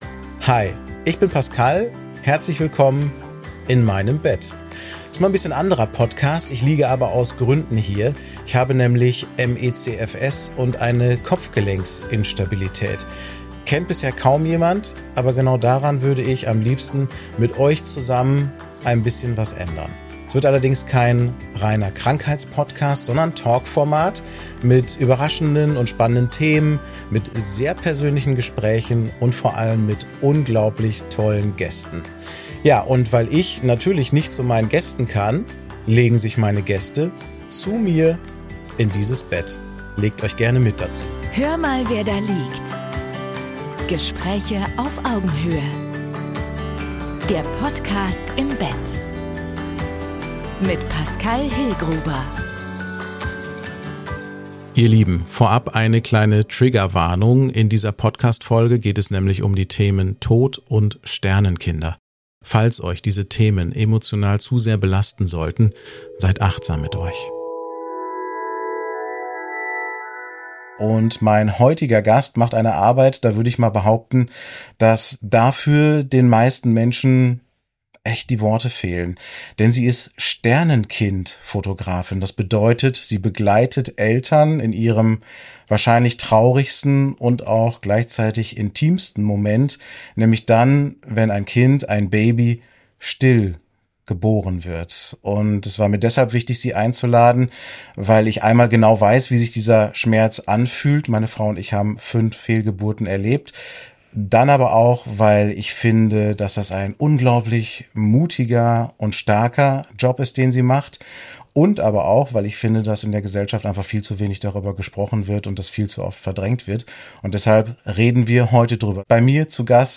Ein bewegendes Gespräch über Liebe, Verlust, Mitgefühl – und darüber, warum es so wichtig ist, diesem Thema einen Platz zu geben.